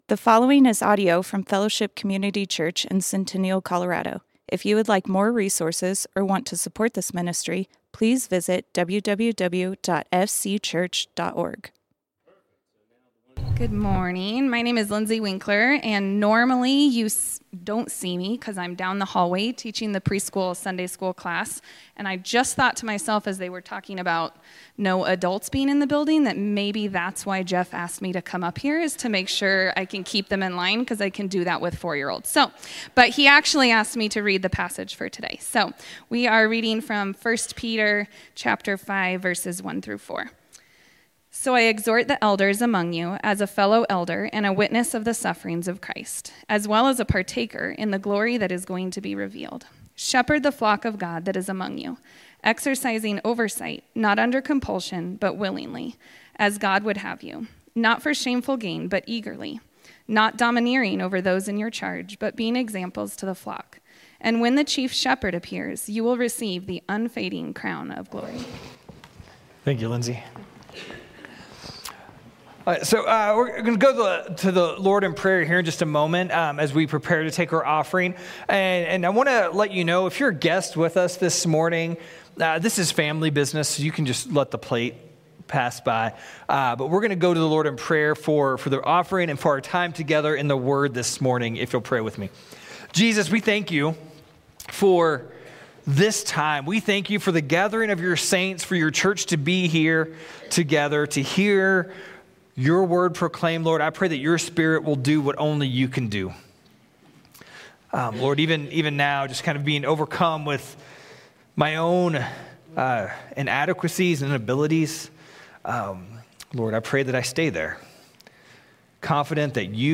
Fellowship Community Church - Sermons Our Chief Shepherd Play Episode Pause Episode Mute/Unmute Episode Rewind 10 Seconds 1x Fast Forward 30 seconds 00:00 / 37:35 Subscribe Share RSS Feed Share Link Embed